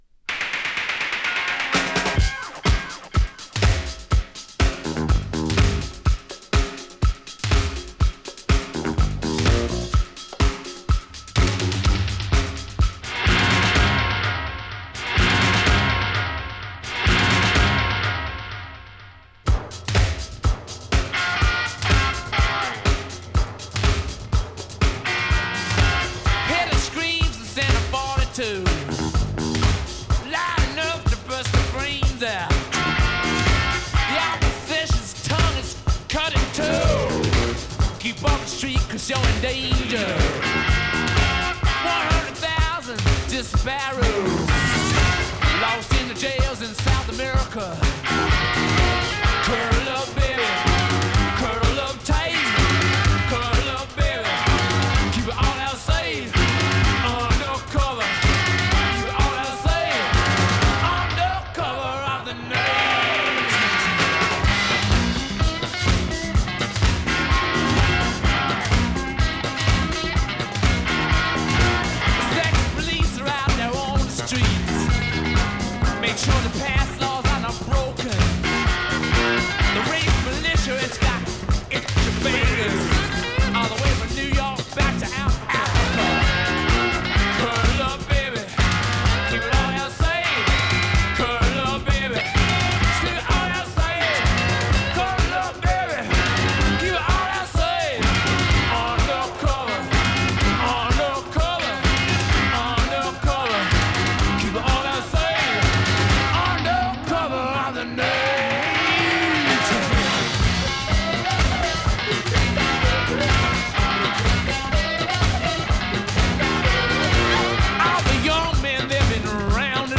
Ze zijn trouwens niet mono, maar stereo.